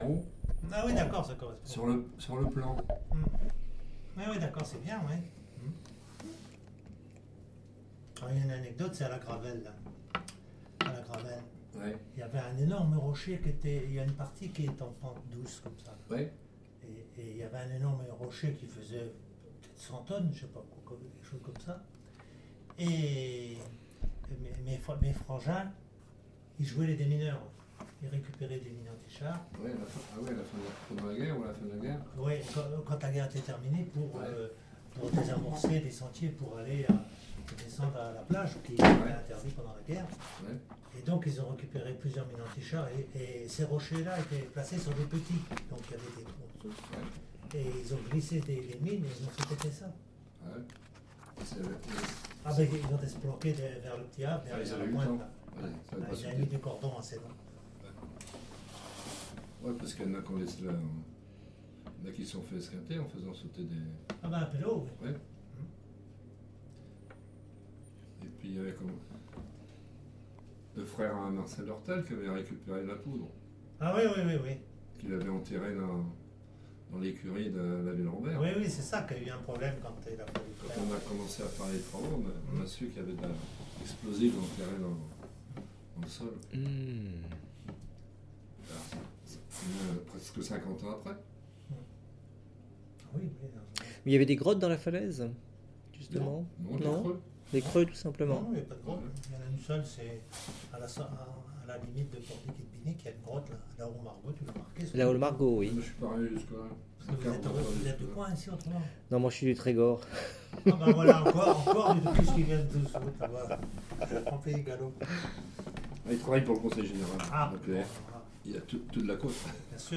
Témoignage oral